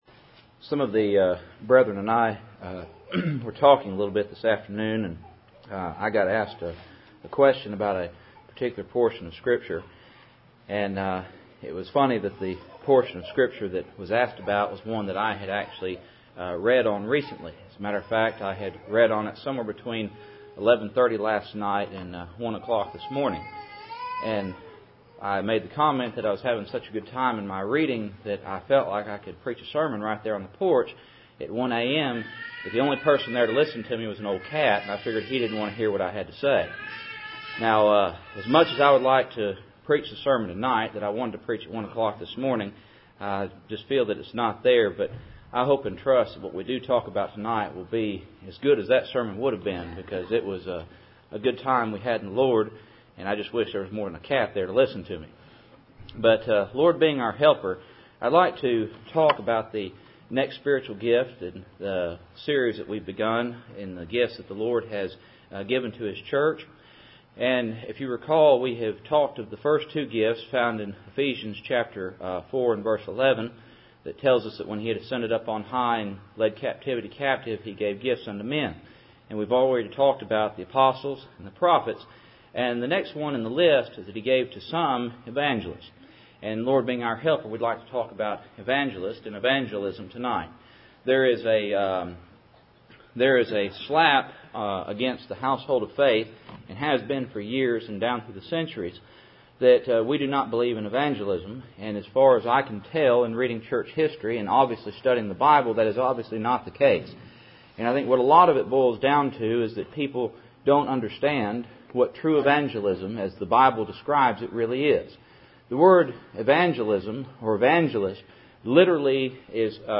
Sunday Evening